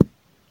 beeb kick 16
Tags: 808 drum cat kick kicks hip-hop